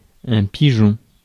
Ääntäminen
US : IPA : [ˈpɪ.dʒən] UK : IPA : /ˈpɪdʒɪn/